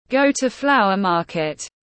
Đi chợ hoa tiếng anh gọi là go to flower market, phiên âm tiếng anh đọc là /ɡəʊ tuː flaʊər ˈmɑː.kɪt/
Go to flower market /ɡəʊ tuː flaʊər ˈmɑː.kɪt/
Go-to-flower-market-.mp3